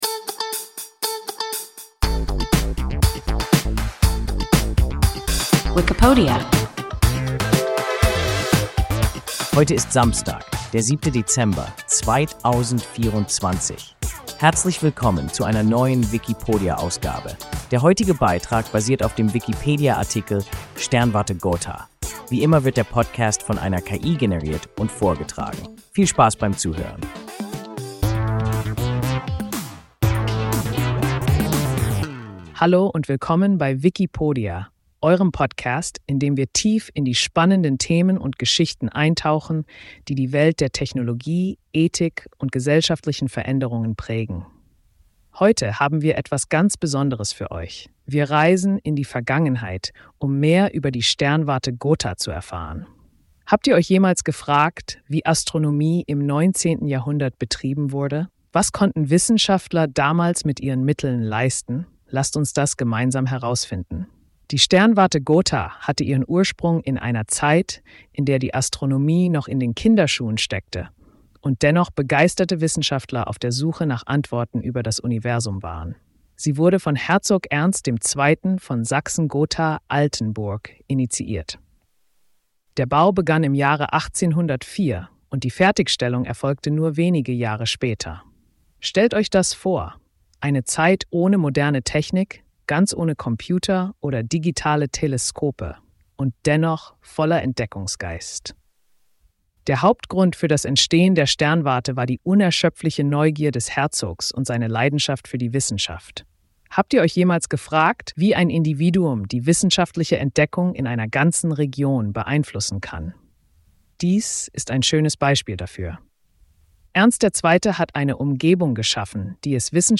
Sternwarte Gotha – WIKIPODIA – ein KI Podcast